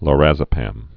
(lôr-ăzə-păm)